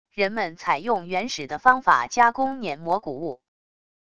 人们采用原始的方法加工辗磨谷物wav音频